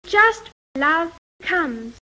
His own STRUT vowel sounds more central, [ʌ̈]: